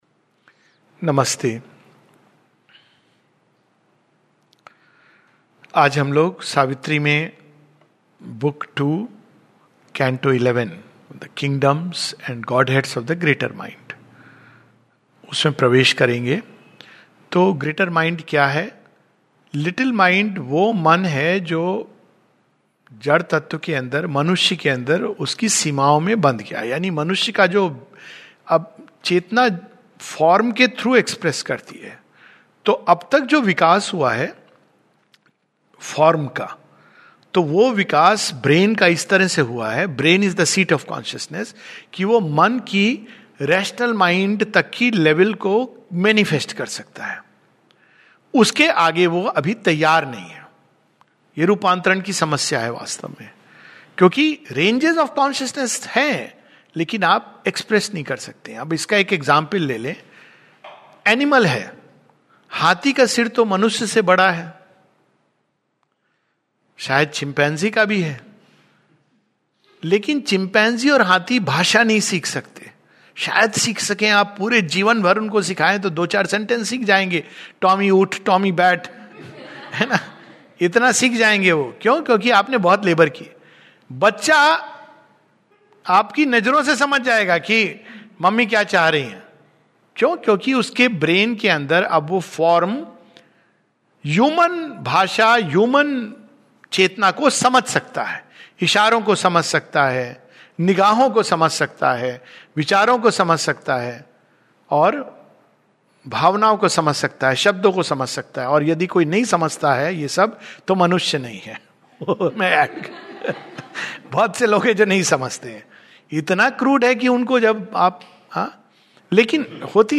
This talk is a summary of Book Two Canto Eleven of Savitri. Aswapati now leaves the limits of the labouring little mind and enters into a wider and more luminous domain. Here he experiences a change in the functioning of the mind and its means of knowing and understanding.